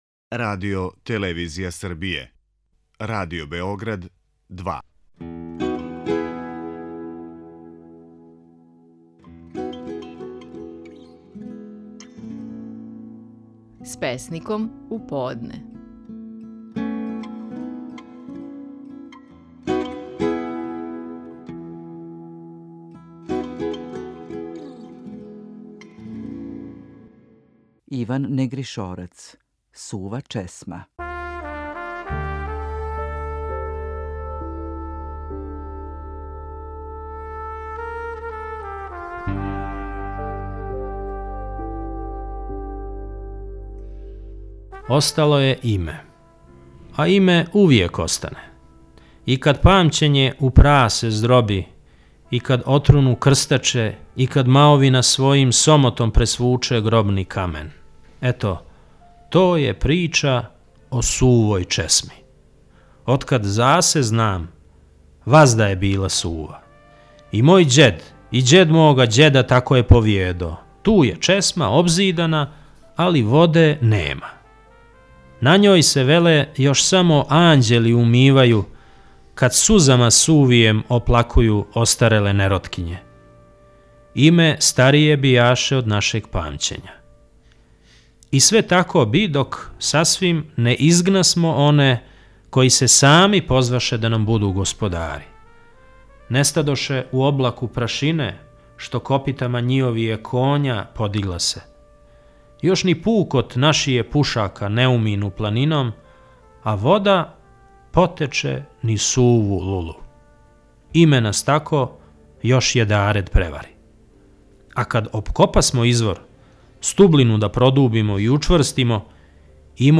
Стихови наших најпознатијих песника, у интерпретацији аутора.
Иван Негришорац говори своју песму „Сува чесма".